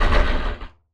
PixelPerfectionCE/assets/minecraft/sounds/mob/guardian/elder_hit4.ogg at mc116
elder_hit4.ogg